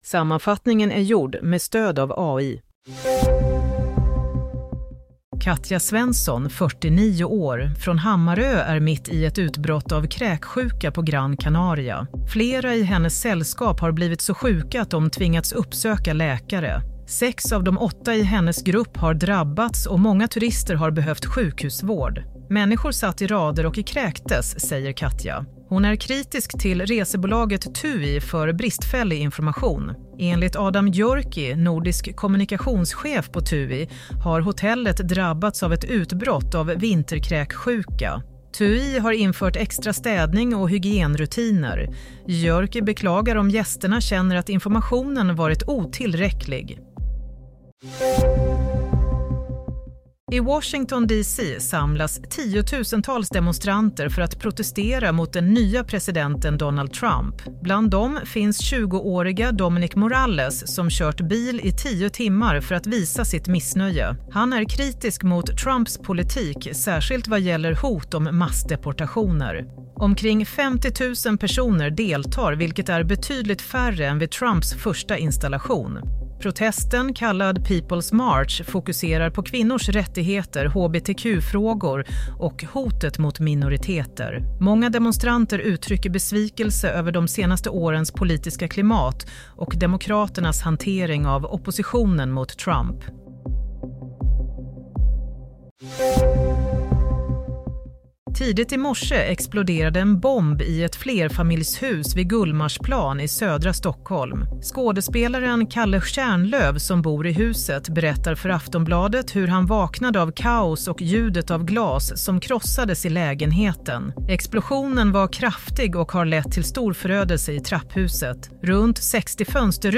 Nyhetssammanfattning – 18 januari 22:00